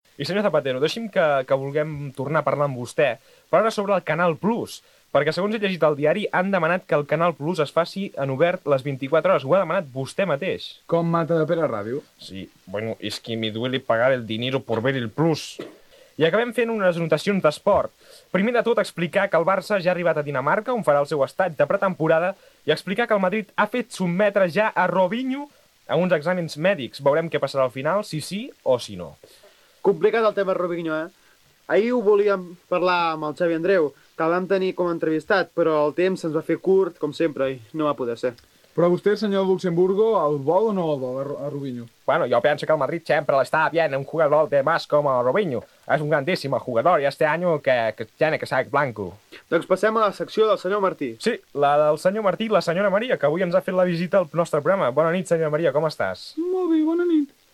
Imitacions dels personatges fetes per Ricard Ustrell del president Zapartero i d'alguns personatges futbolístics
Entreteniment